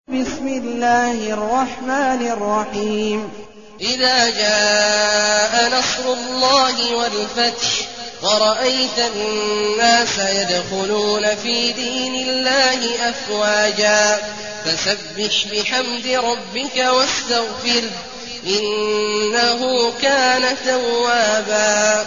المكان: المسجد النبوي الشيخ: فضيلة الشيخ عبدالله الجهني فضيلة الشيخ عبدالله الجهني النصر